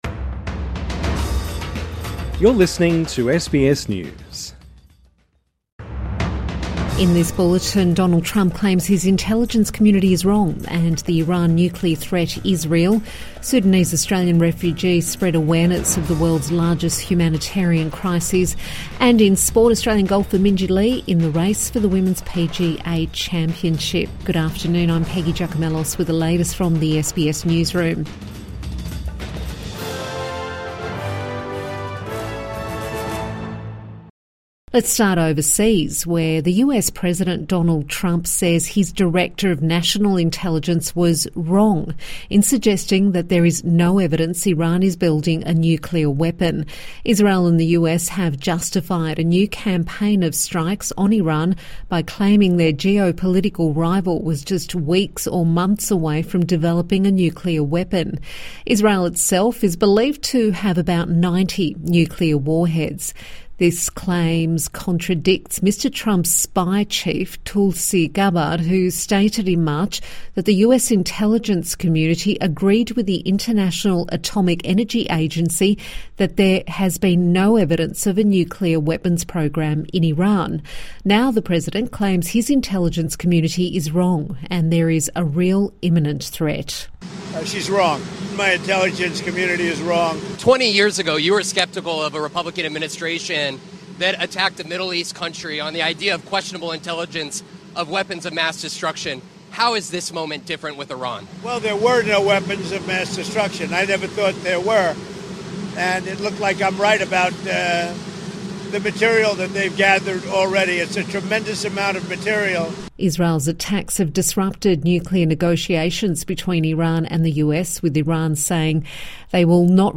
Evening News Bulletin 21 June 2025